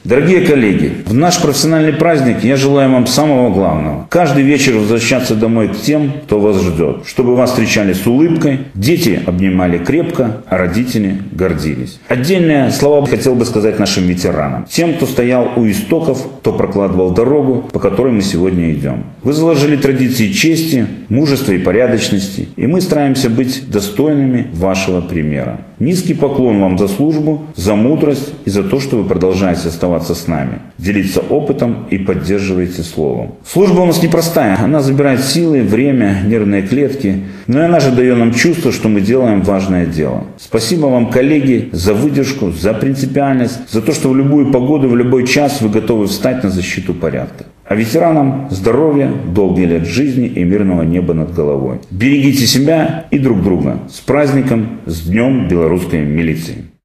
Свои поздравления в адрес личного состава направил начальник городского отдела внутренних дел полковник милиции Виталий Хвесько.